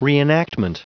Prononciation du mot reenactment en anglais (fichier audio)
Prononciation du mot : reenactment
reenactment.wav